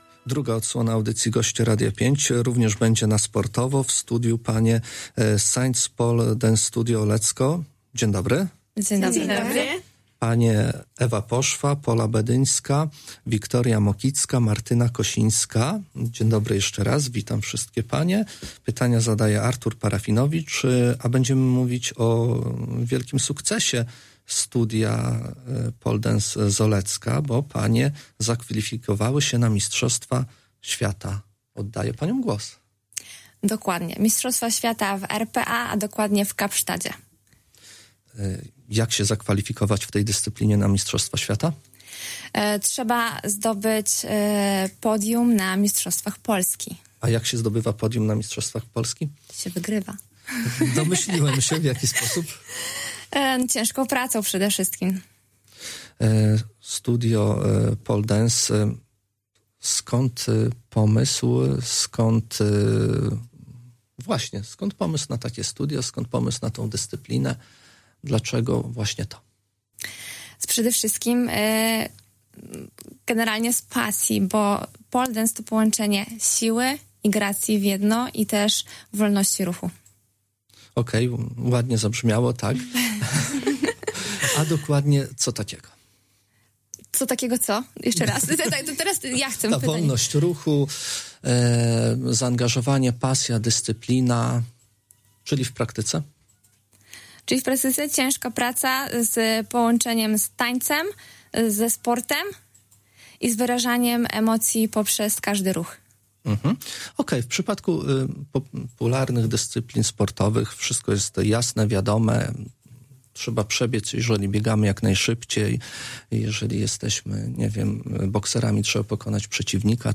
O swojej pasji i zbiórce opowiadały w piątek (17.10) w Radiu 5.